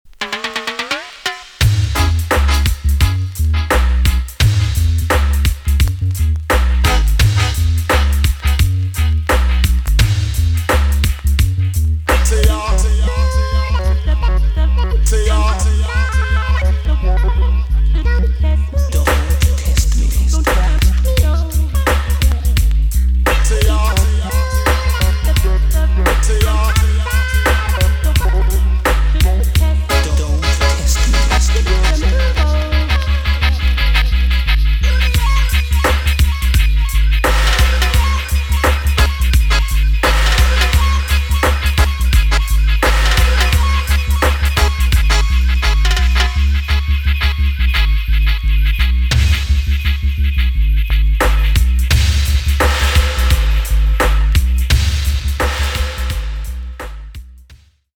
B.SIDE Version
EX- 音はキレイです。
1990 , RARE , WICKED DANCEHALL TUNE!!